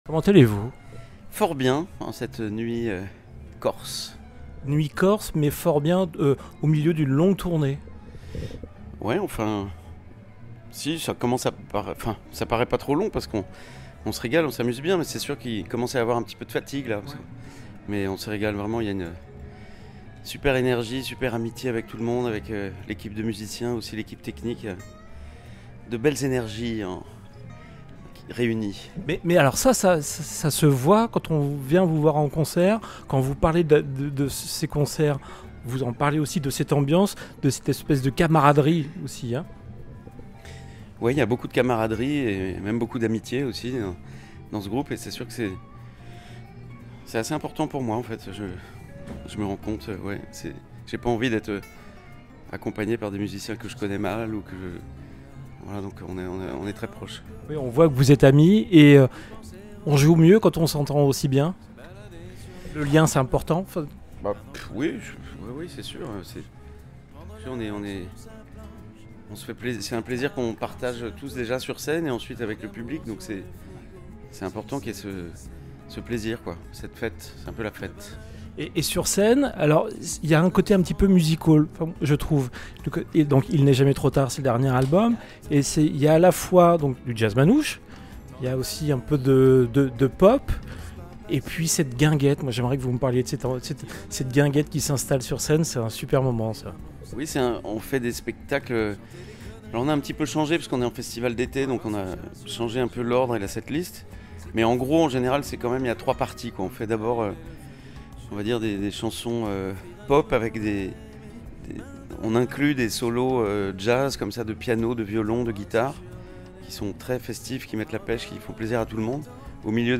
Une interview, où l'artiste se confie en toute transparence sur ses secrets de fabrication d'un titre
Écoutez l'interview du truculent musicien Thomas Dutronc à l'occasion du festival des Nuits de la Guitare de Patrimonio pour cette saison 2025 !